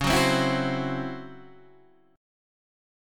C# Minor Major 7th Double Flat 5th